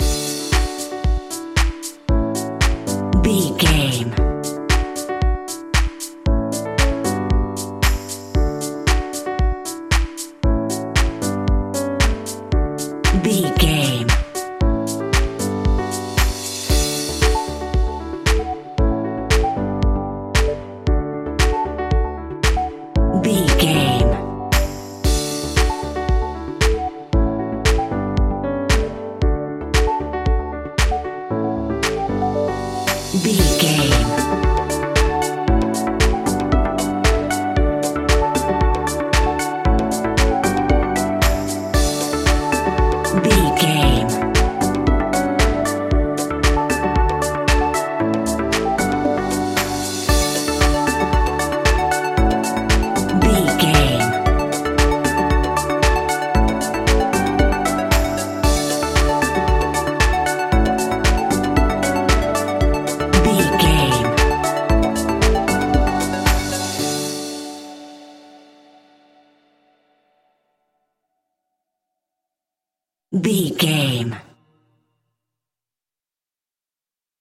Ionian/Major
D
groovy
energetic
uplifting
hypnotic
drum machine
synthesiser
piano
house
electro house
synth leads
synth bass